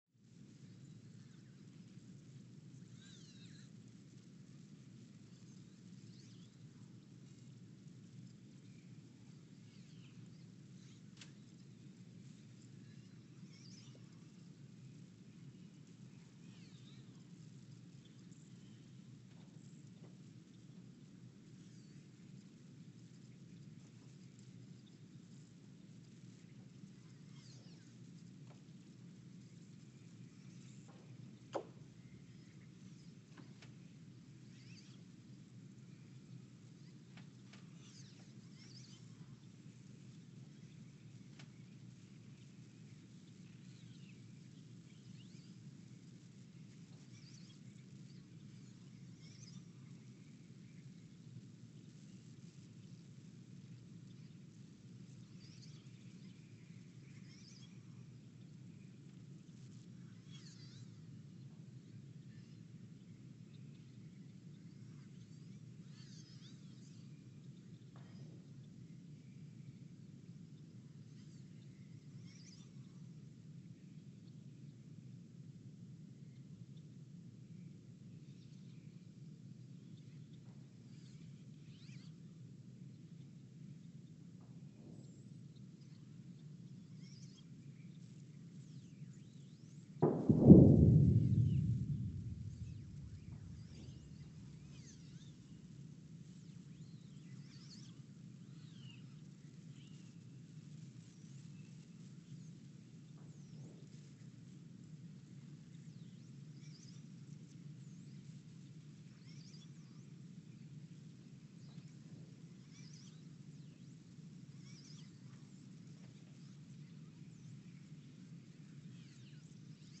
Ulaanbaatar, Mongolia (seismic) archived on January 30, 2024
Sensor : STS-1V/VBB
Speedup : ×900 (transposed up about 10 octaves)
Loop duration (audio) : 03:12 (stereo)
SoX post-processing : highpass -2 90 highpass -2 90